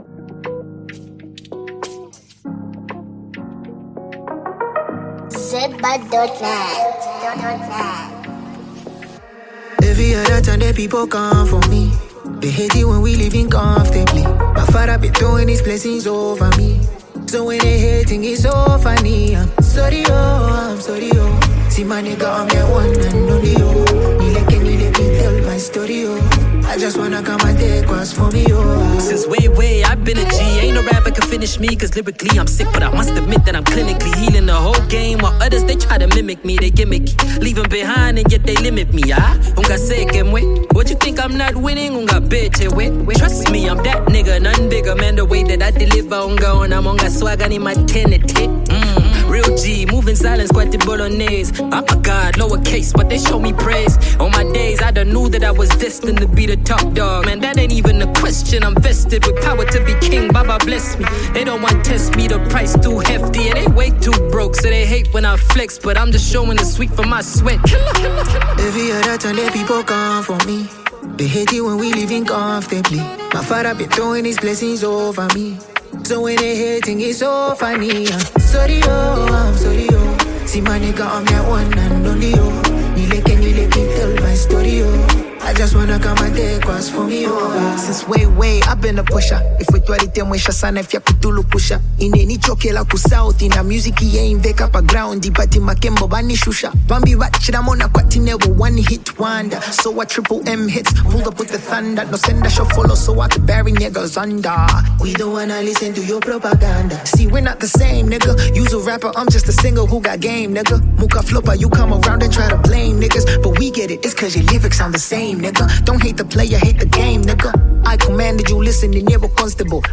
The beat is strong and the lyrics are catchy.